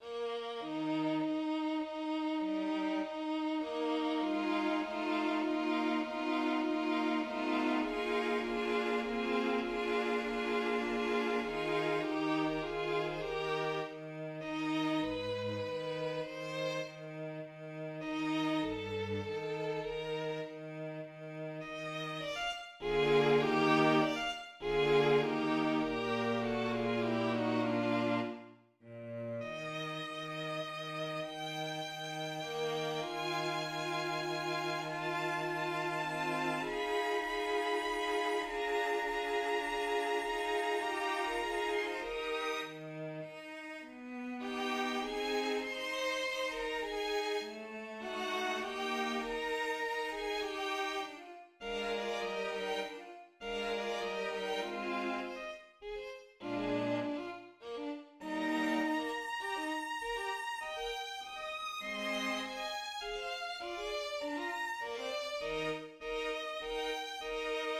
2. Andante, in Mi bemolle maggiore, tempo 6/8.
Il secondo movimento ha un metro di 6/8 ed è in Mi bemolle maggiore, che è la sopradominante maggiore della tonalità di Sol minore, in cui è composto il resto della sinfonia. Il motivo iniziale è enunciato in piano dagli archi. I fiati si inseriscono rispondendo a una figura che fungerà, in seguito, da elemento di dialogo fra le sezioni.